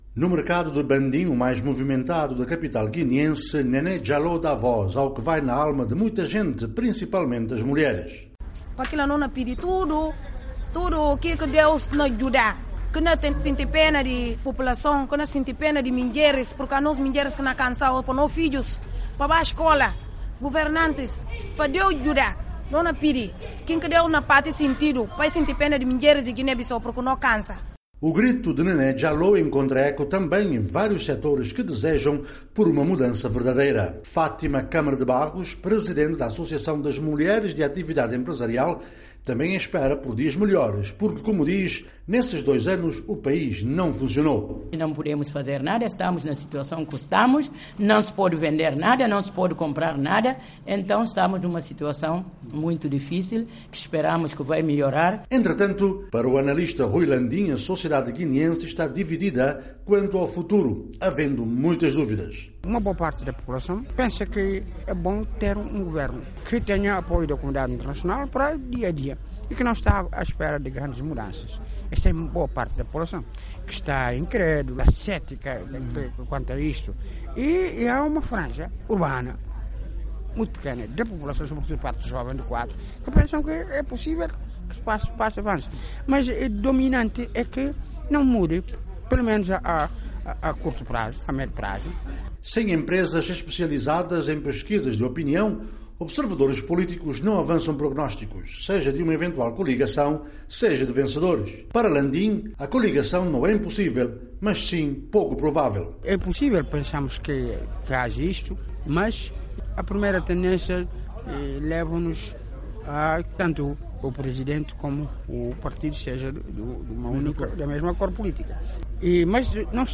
Reportagem no mercado de Bandim com vendedeiras - 3:50